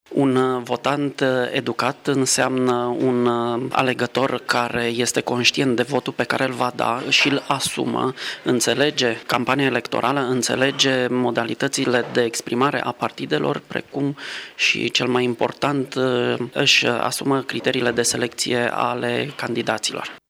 Cu o oarecare întârziere din cauza vacanţei şcolare, Autoritatea Electorală Permanentă a organizat astăzi, la Universitatea „Petru Maior” din Tîrgu-Mureş, un workshop.